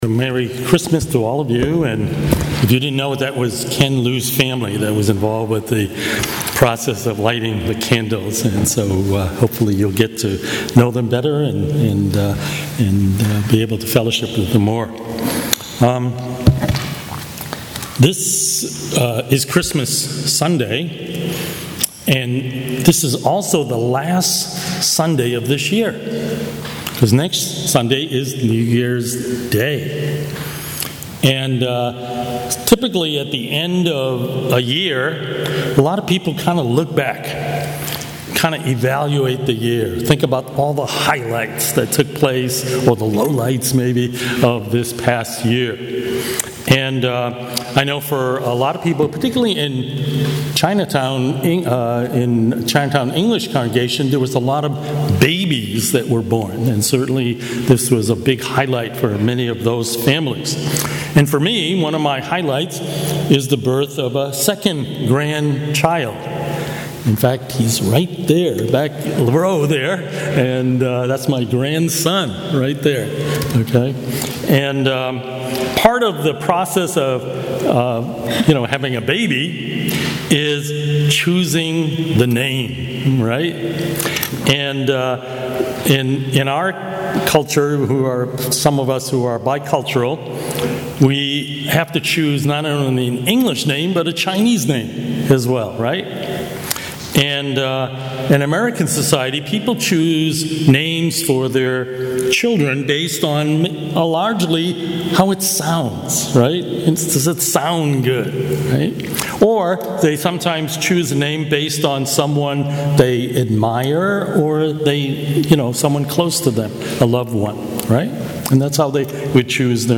Newton English Service (11:00am) - Page 17 of 34 | Boston Chinese Evangelical Church